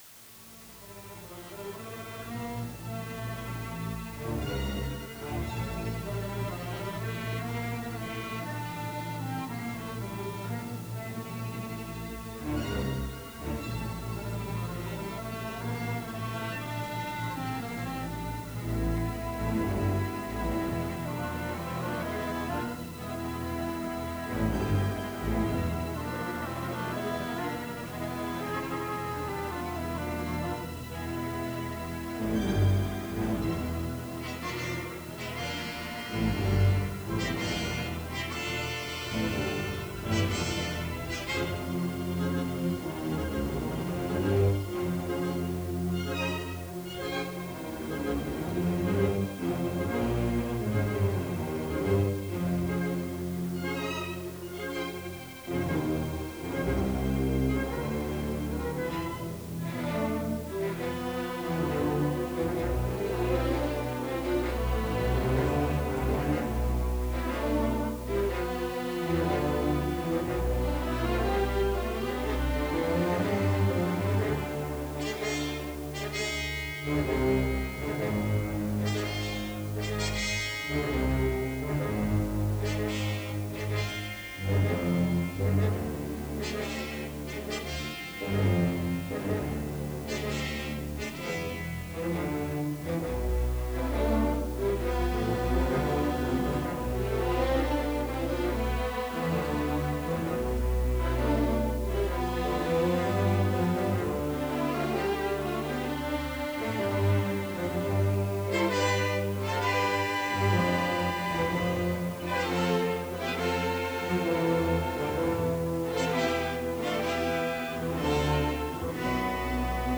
vigorosa colonna sonora
Original track music